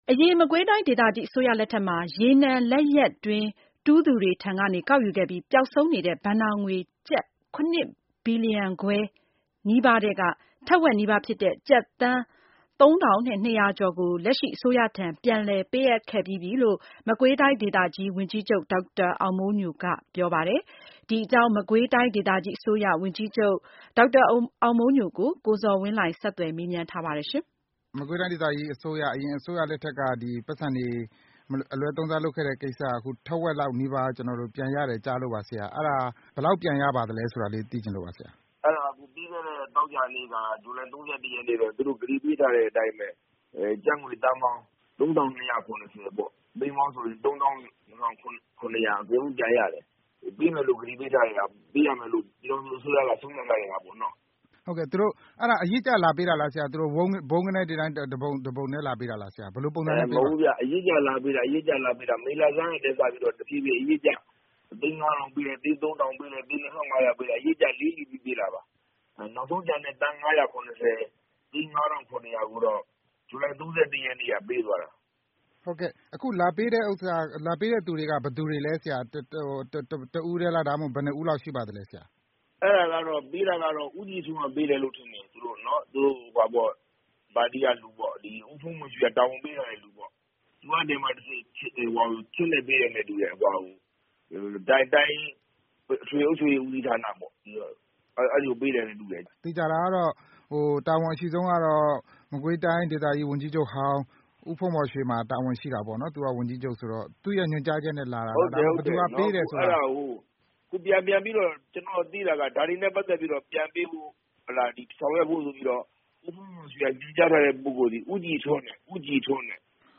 ဆက်သွယ် မေးမြန်းထားပါတယ်။